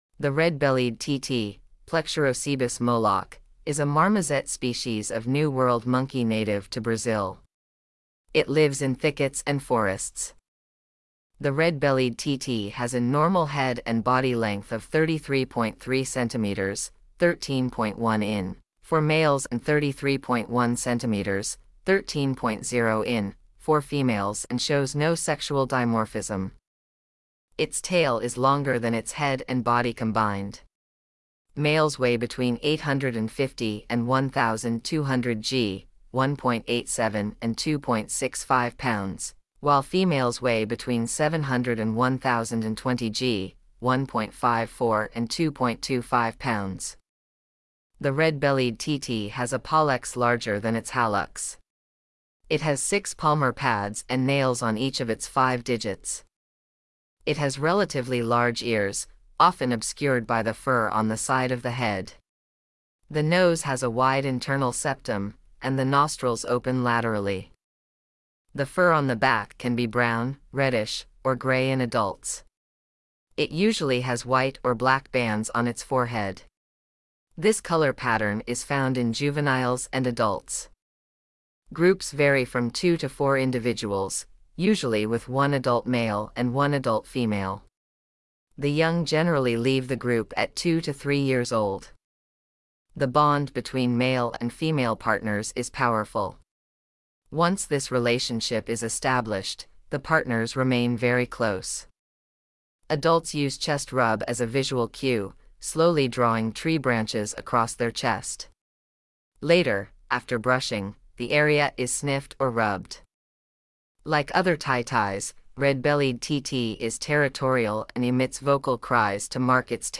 Red-bellied Titi
• Like other titis, red-bellied titi is territorial and emits vocal cries to mark its territory and scare other animals.
Red-bellied-Titi.mp3